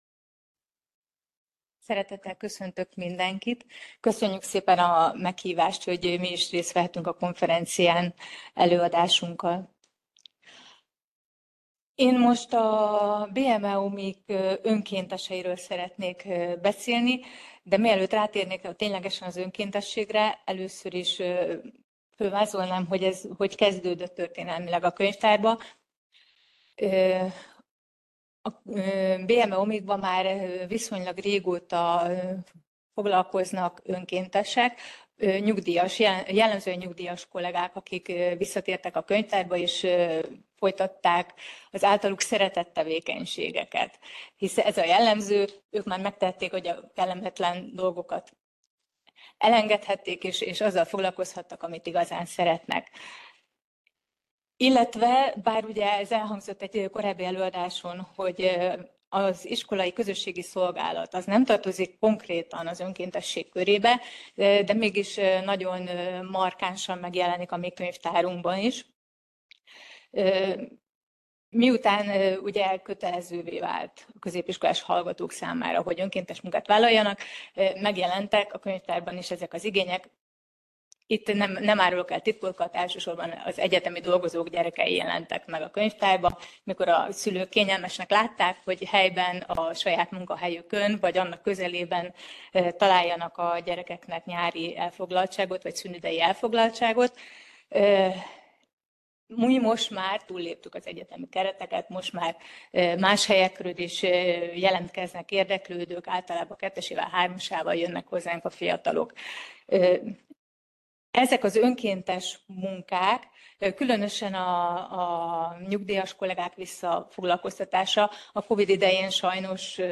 Elhangzott a Központi Statisztikai Hivatal Könyvtár és a Magyar Könyvtárosok Egyesülete Társadalomtudományi Szekciója Szakkönyvtári seregszemle 2025 című